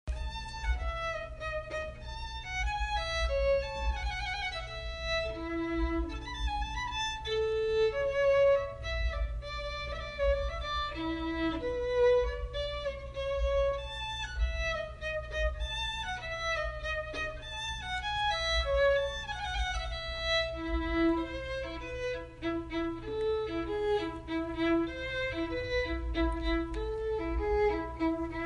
Download Violin sound effect for free.
Violin